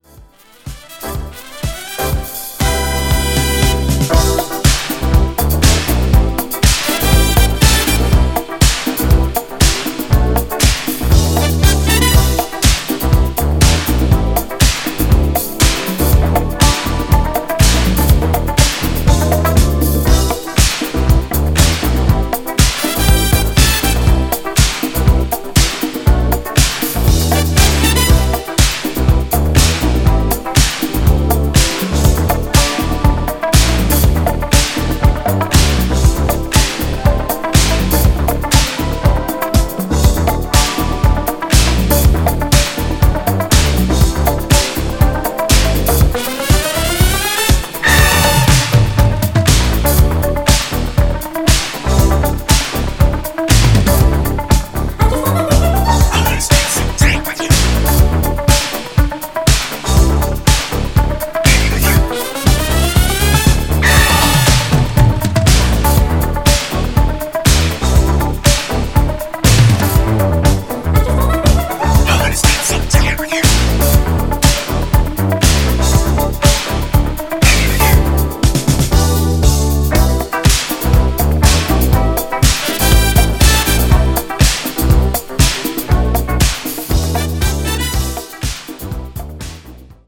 ジャンル(スタイル) DISCO / HOUSE